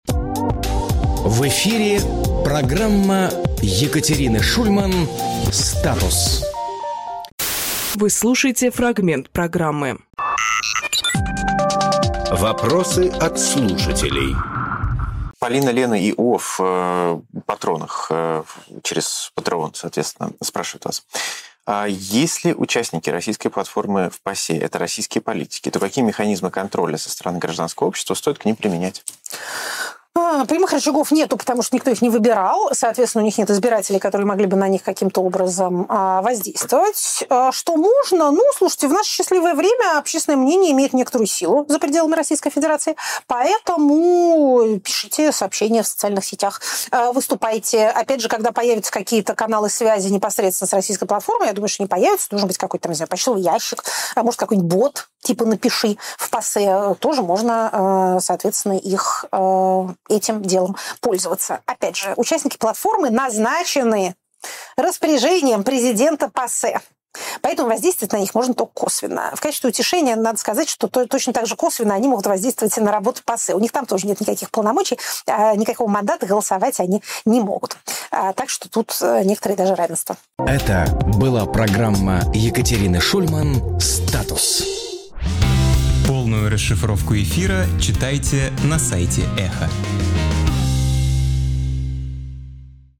Екатерина Шульманполитолог
Максим Курниковглавный редактор «Эха», журналист
Фрагмент эфира от 03.02.26